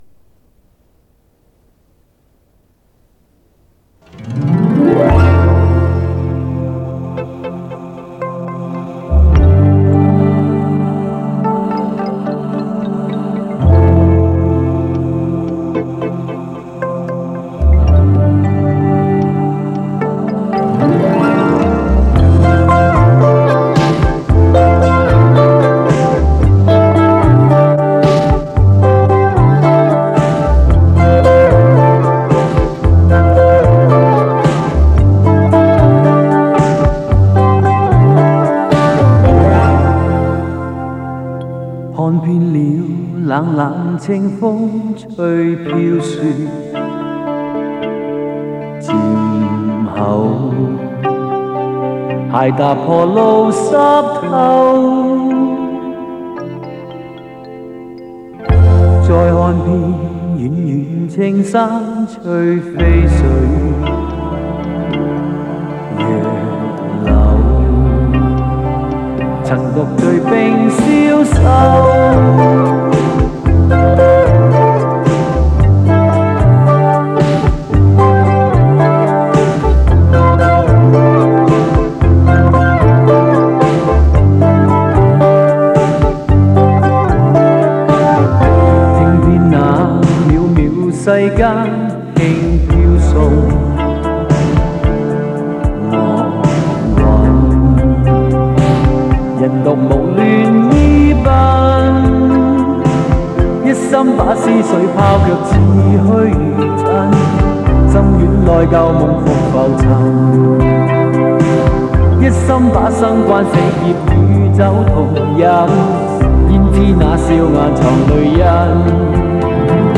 整首歌正是这一点点的不协和感令人反复咀嚼把玩。